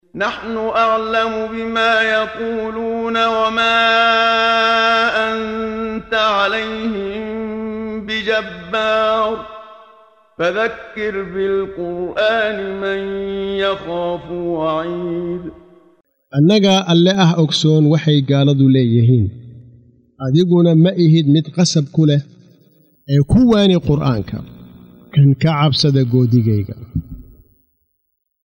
Waa Akhrin Codeed Af Soomaali ah ee Macaanida Suuradda Qaaf oo u kala Qaybsan Aayado ahaan ayna la Socoto Akhrinta Qaariga Sheekh Muxammad Siddiiq Al-Manshaawi.